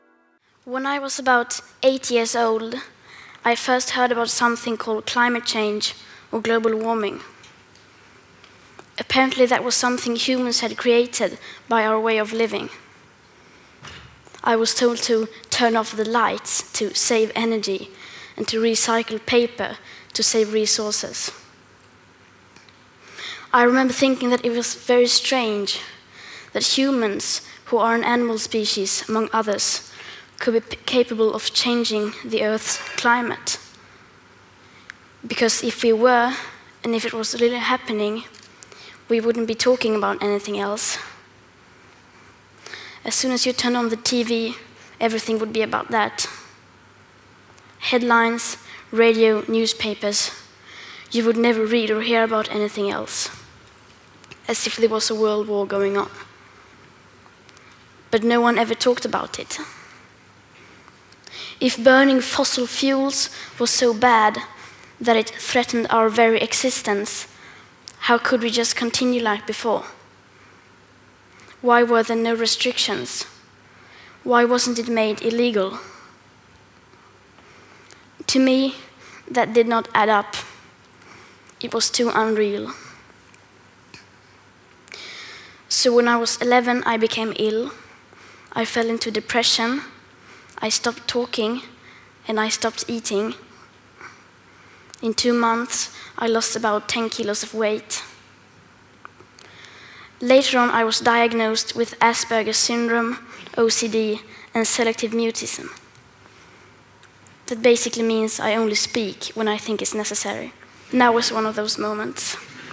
climate change speech Greta Thunberg.wav
young Greta Thunberg speaking about climate change, as a young girl living in Sweden.
climate_change_speech_Greta_Thunberg_OZd.wav